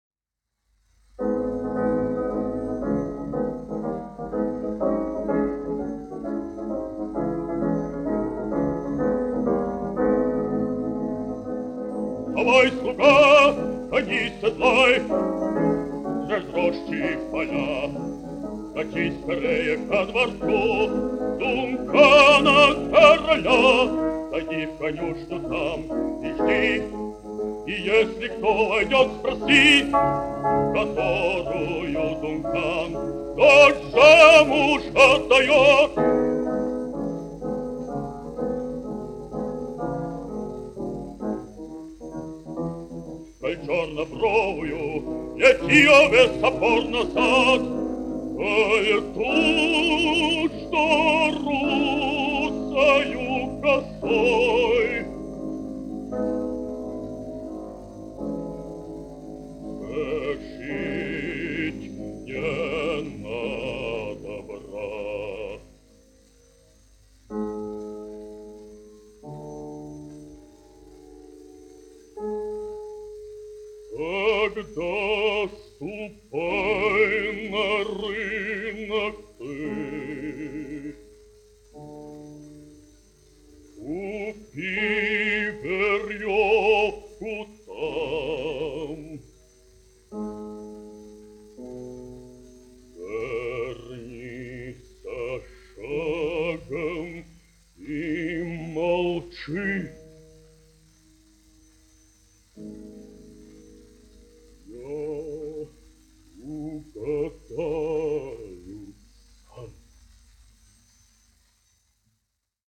1 skpl. : analogs, 78 apgr/min, mono ; 25 cm
Dziesmas (vidēja balss) ar klavierēm
Latvijas vēsturiskie šellaka skaņuplašu ieraksti (Kolekcija)